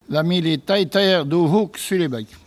Localisation Perrier (Le)
Catégorie Locution